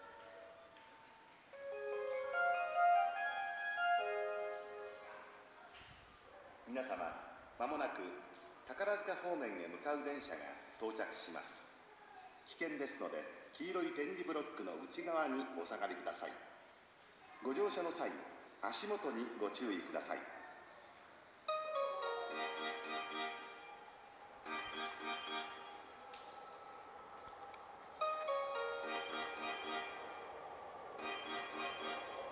この駅では接近放送が設置されています。
接近放送各駅停車　宝塚行き接近放送です。